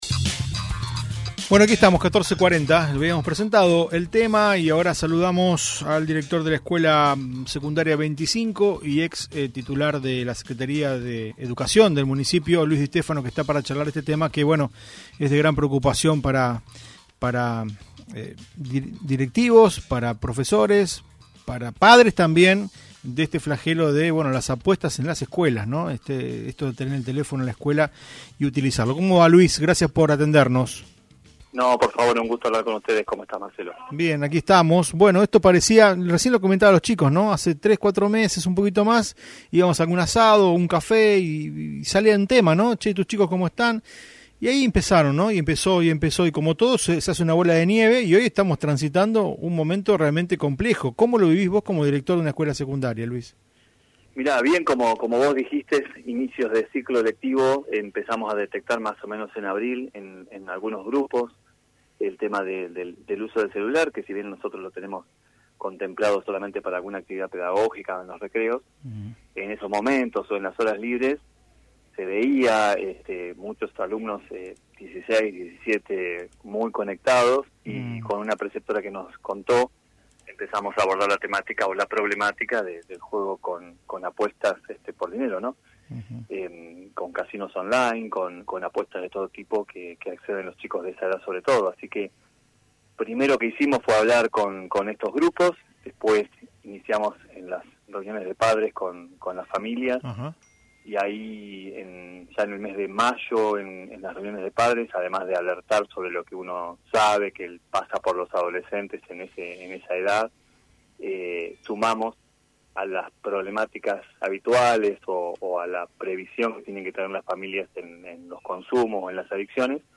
en diálogo con "UPM" de Mite (FM. 103.7).